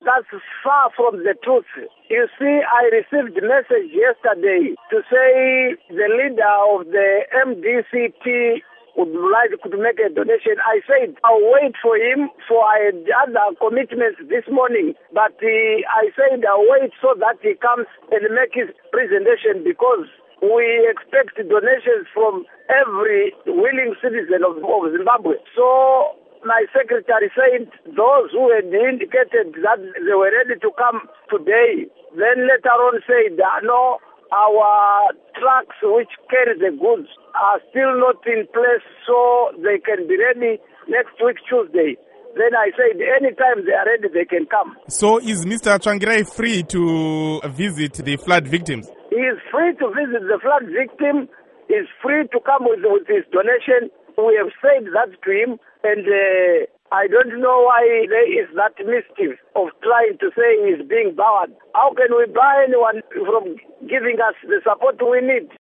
Interview With Kudakwashe Bhasikiti